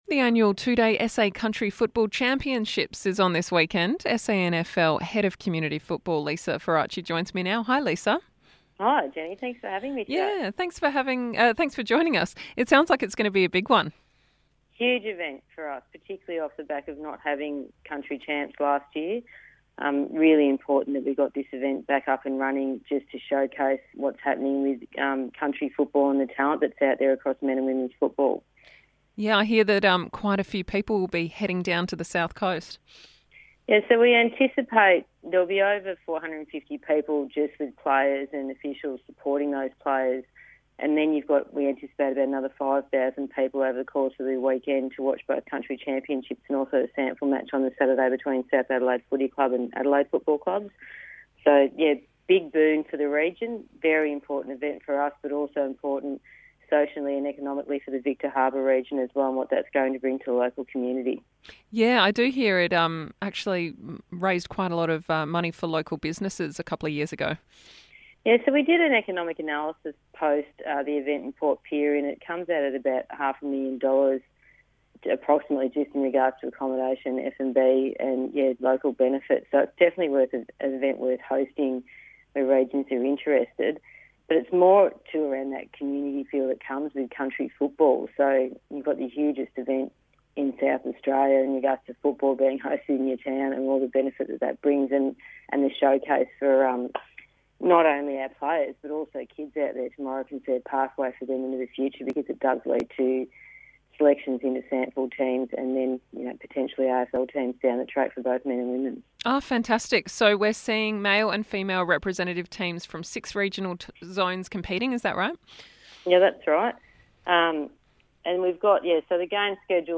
in this podcast for a chat about it and the Statewide Super